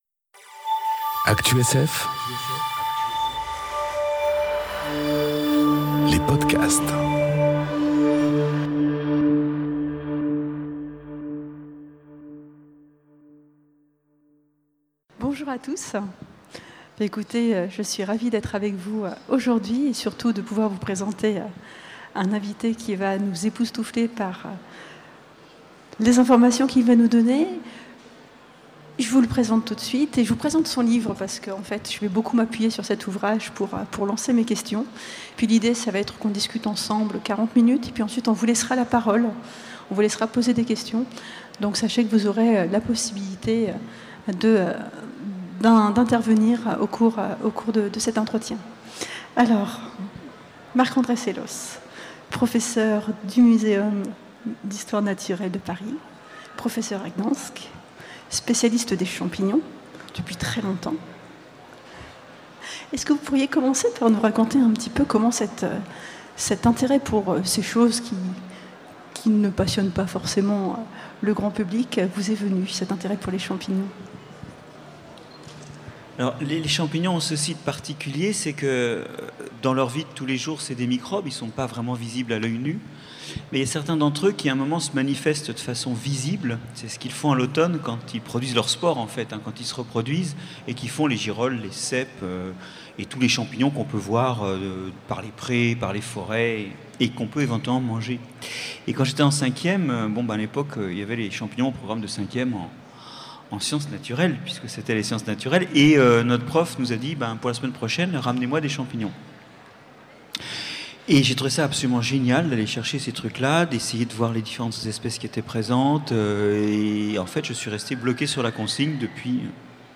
Rencontre avec celui qui parle aux microbes enregistrée aux Utopiales 2018